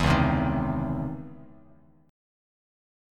C#mM9 chord